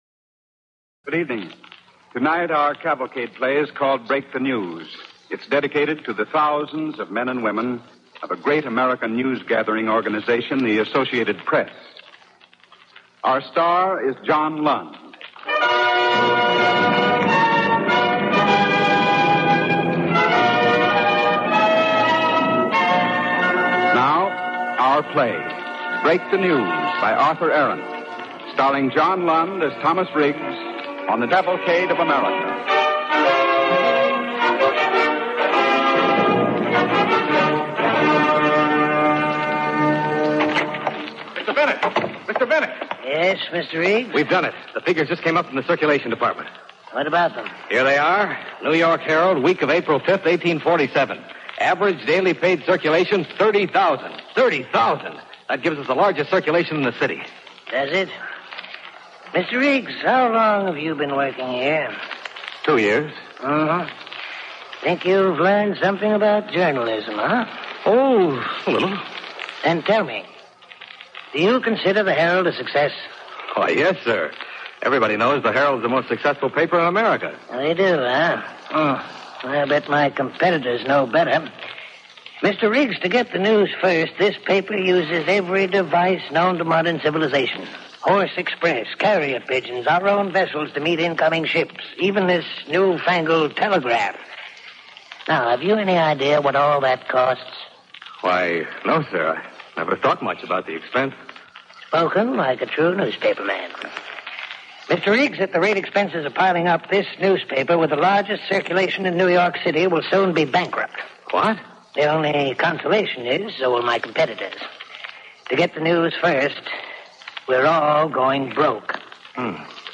Break the News, starring John Lund and Parker Fennelly